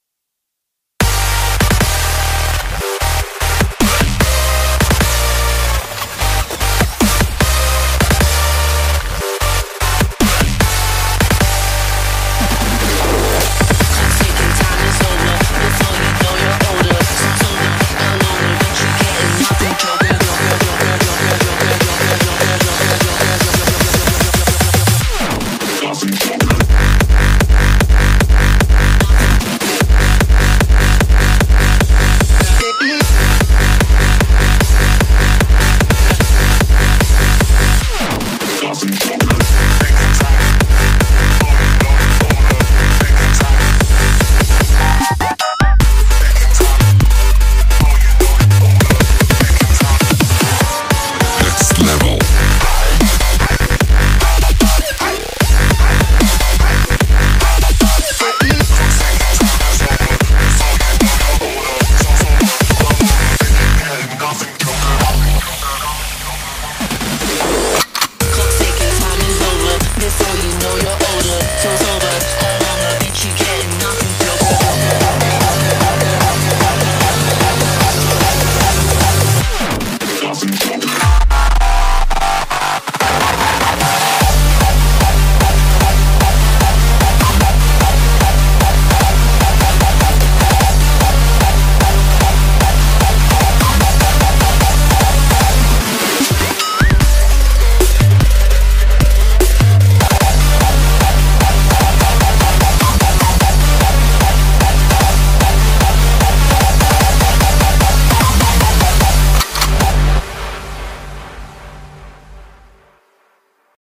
BPM75-150
Comments[HARDSTYLE]